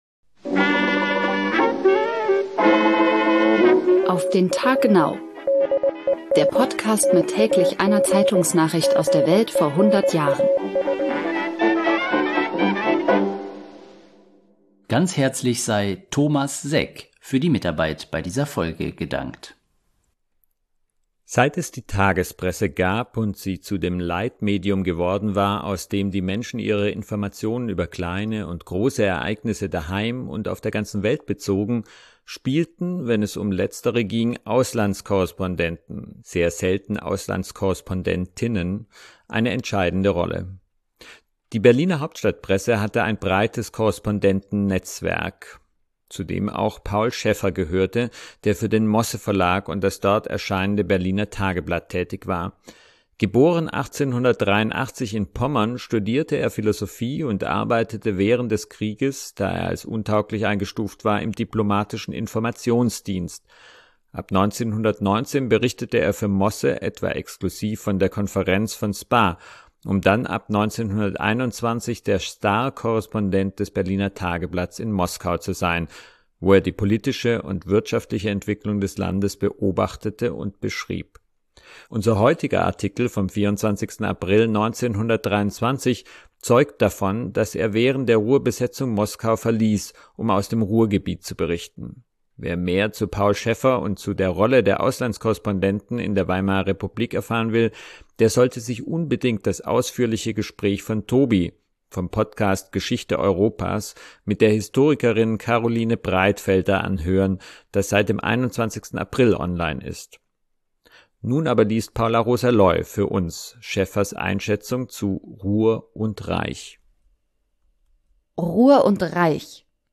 liest